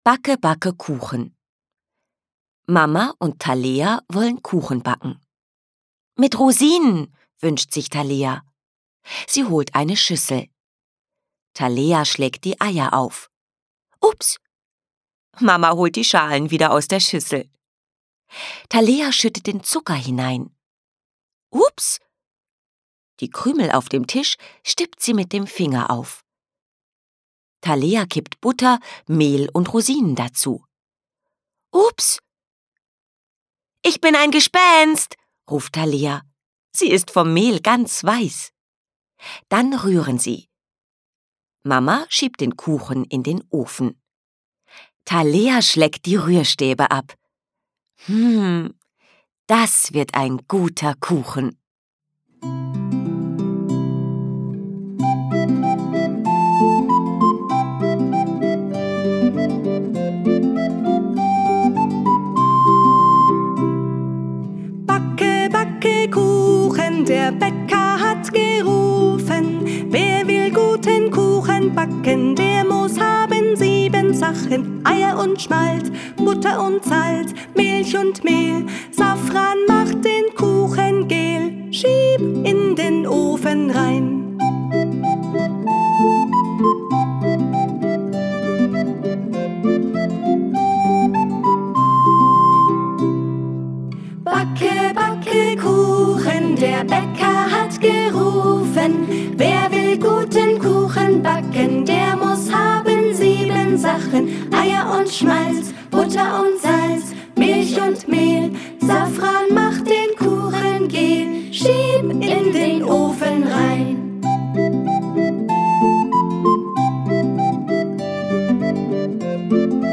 Hörbuch: Meine erste Kinderbibliothek.
lebendig und einfühlsam